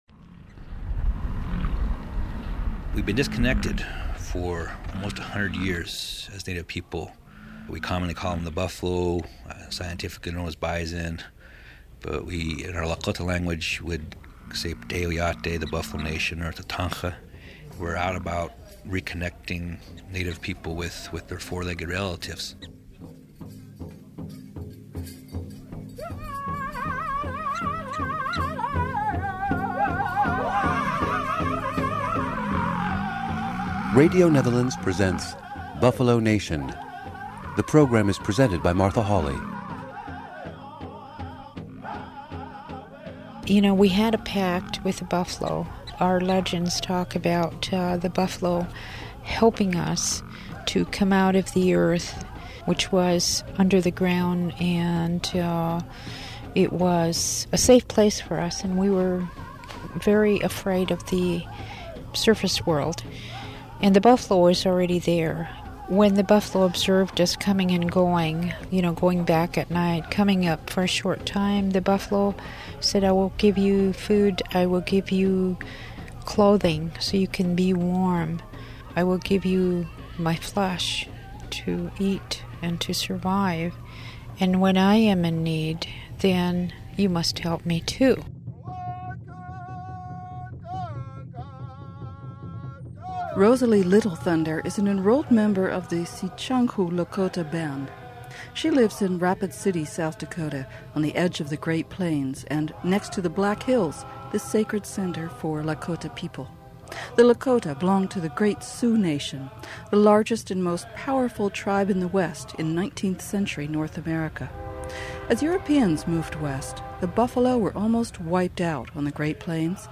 Meat from tribal herds could be sold to reservation schools and hospitals, as well as being kept for ceremonial use. We hear from Native ranchers, caretakers and educators from the Buffalo Nation.